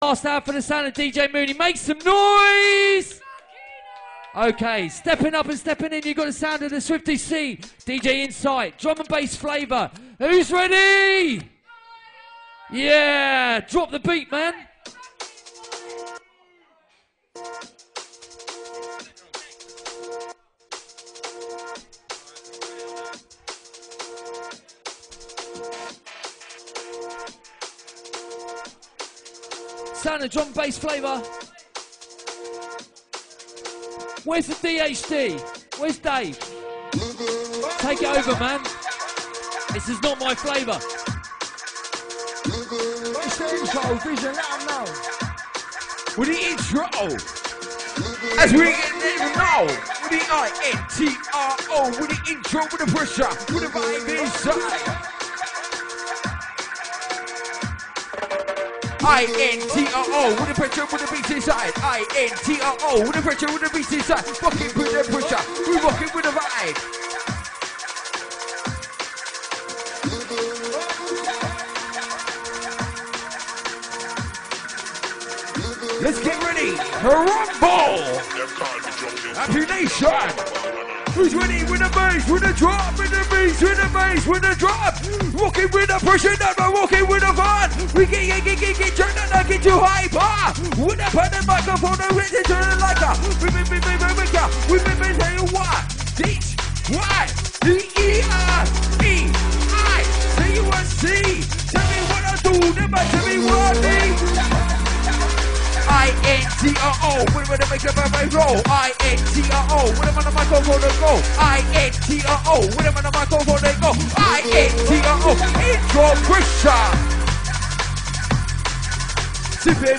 Drum & Bass